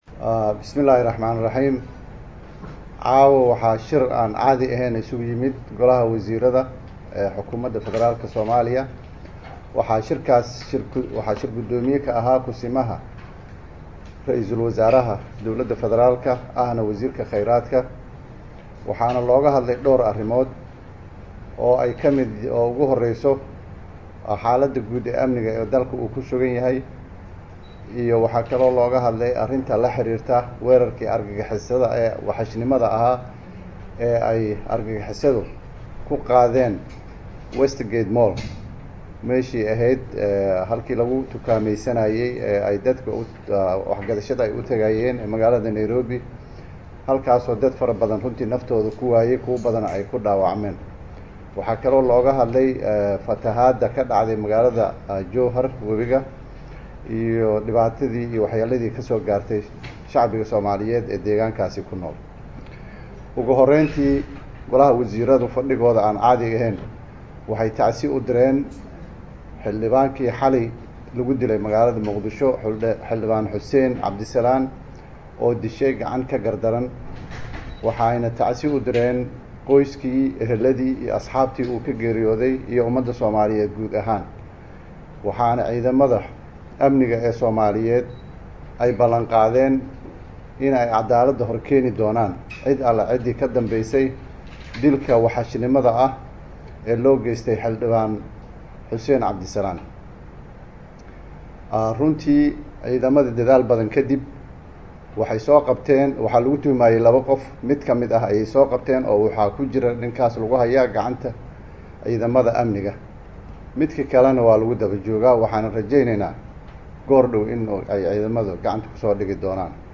Halkan ka dhagayso kulanka Wasiirada